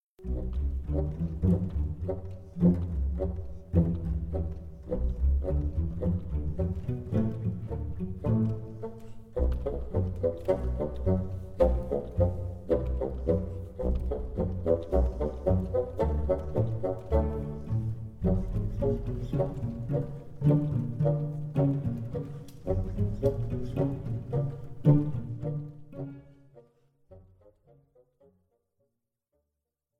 Zuerst das Original, dann mit heruntergeschraubtem Hall:
In der Halle des Bergkönigs (mit natürlichem Hall):